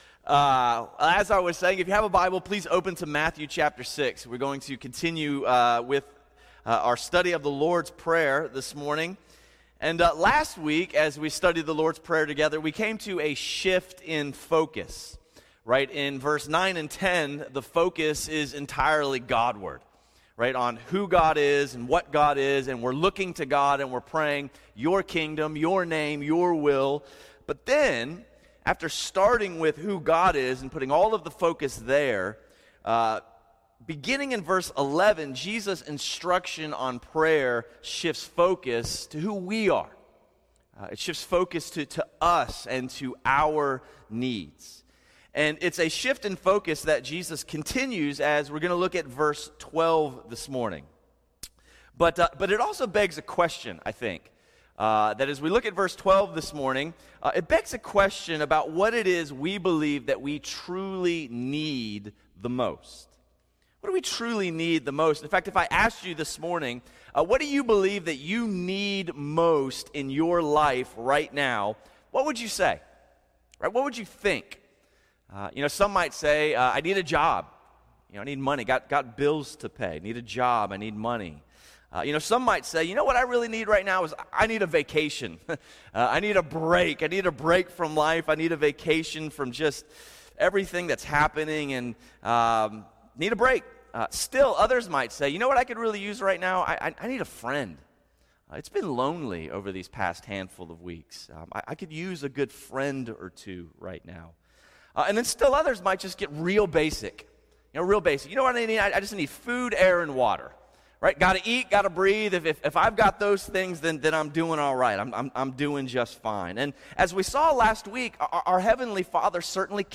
Sunday Sermons – Crossway Community Church